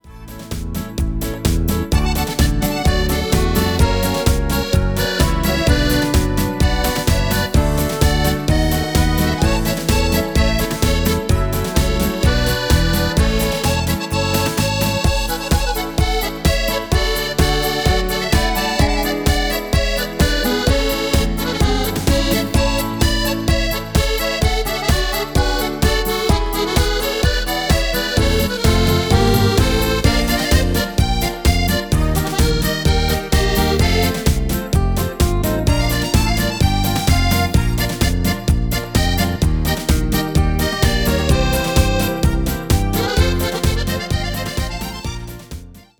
Vocal Harmony Tracks
Key: C